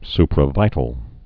(sprə-vītl)